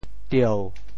tieu7.mp3